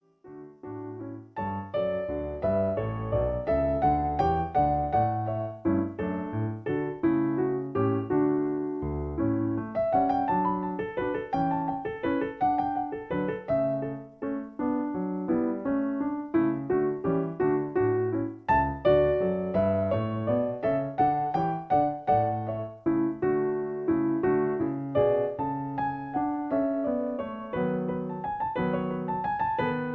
In D. Piano Accompaniment. Air for alto